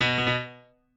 piano4_31.ogg